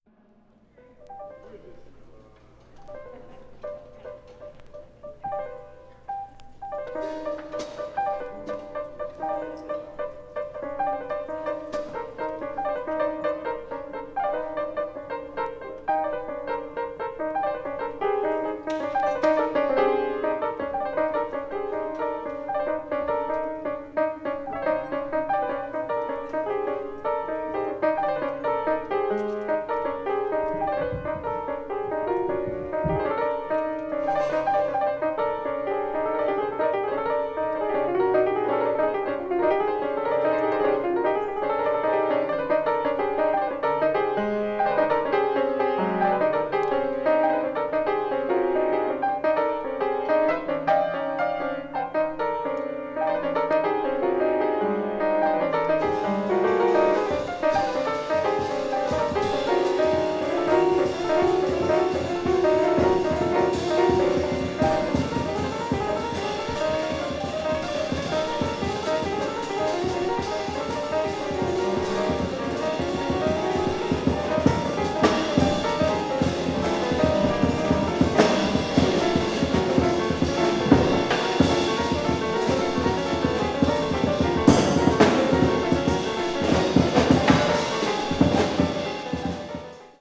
avant-jazz   free improvisaton   free jazz